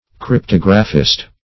Search Result for " cryptographist" : The Collaborative International Dictionary of English v.0.48: Cryptographist \Cryp*tog"ra*phist\ (kr?p-t?g"r?-f?st), n. Same as Cryptographer .
cryptographist.mp3